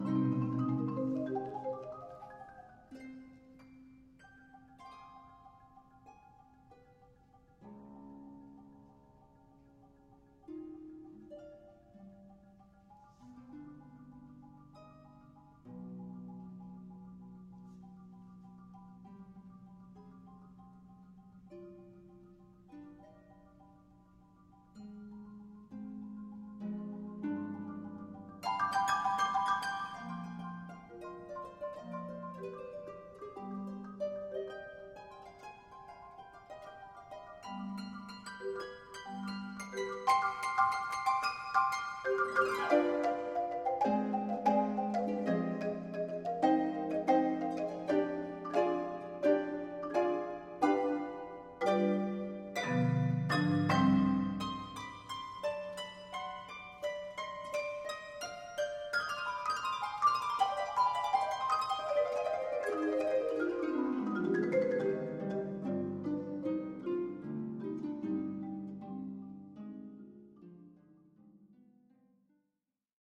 Harp and Marimba
5 octave Marimba, Tam-tam and two bowed crotales (B & F#).